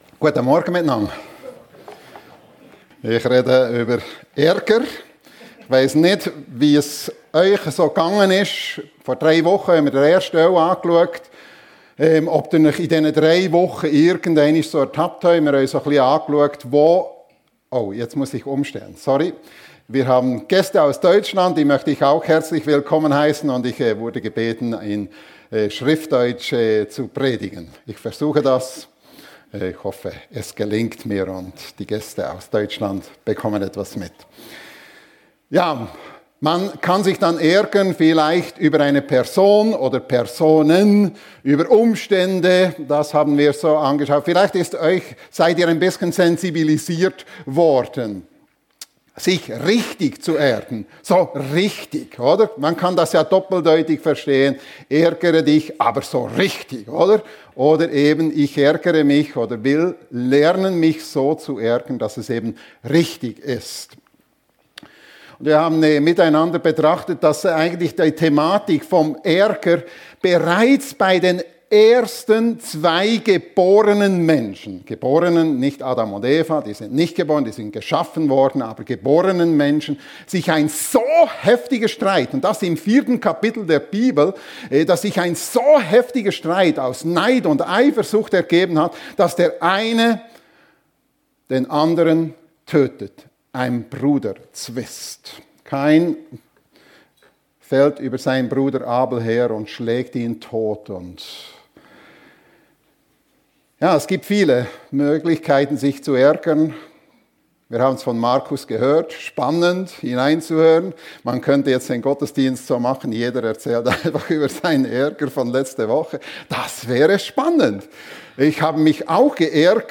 Mensch, ärgere dich - aber richtig (Teil 2) ~ FEG Sumiswald - Predigten Podcast